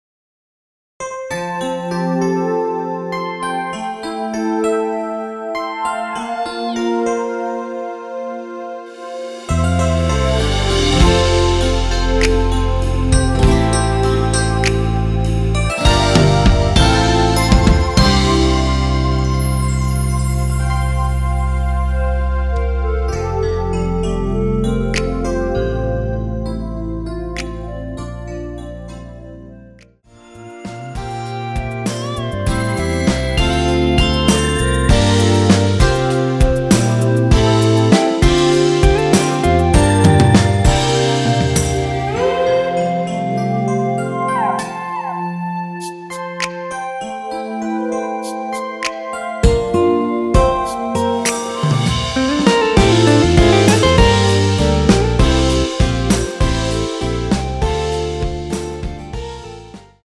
원키에서(+3)올린 2절 삭제한 멜로디 포함된 MR입니다.
멜로디 MR이라고 합니다.
앞부분30초, 뒷부분30초씩 편집해서 올려 드리고 있습니다.